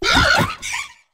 flamigo_ambient.ogg